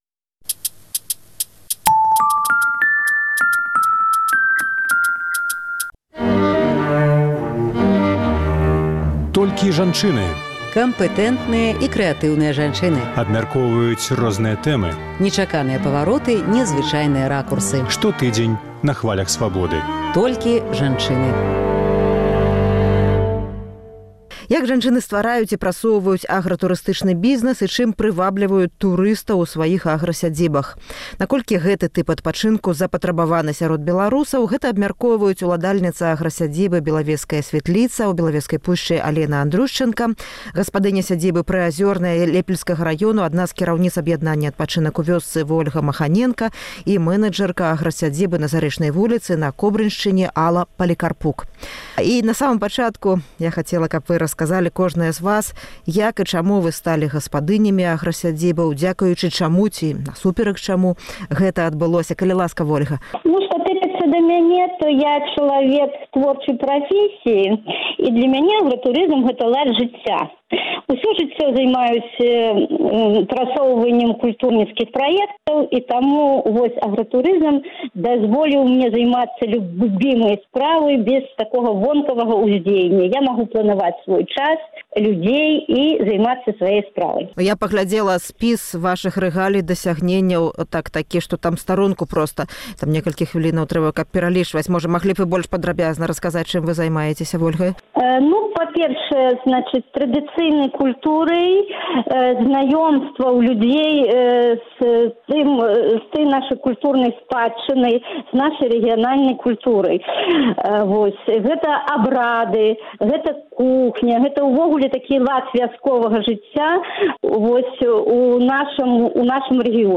Kампэтэнтныя і крэатыўныя жанчыны абмяркоўваюць розныя тэмы, нечаканыя павароты, незвычайныя ракурсы.